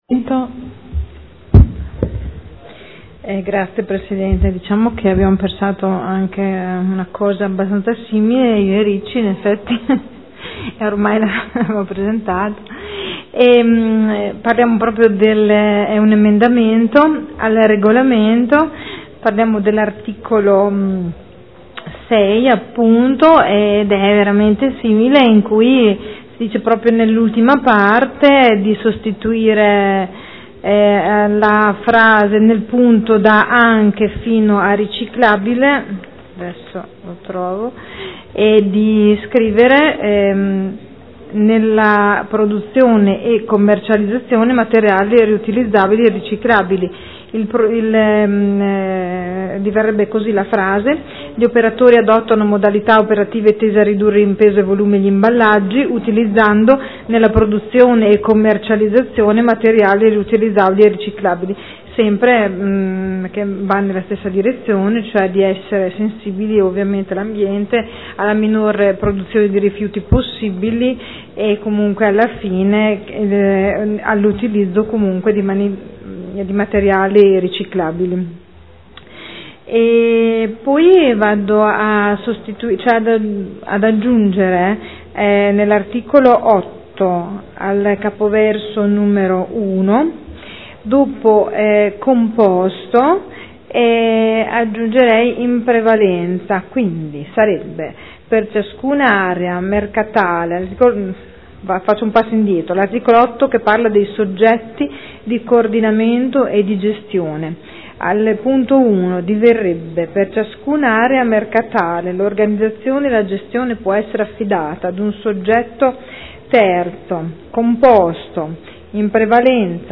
Sandra Poppi — Sito Audio Consiglio Comunale
Seduta del 18/07/2013 Presenta Emendamento. Regolamento per lo svolgimento dei mercati di produttori agricoli nel Comune di Modena.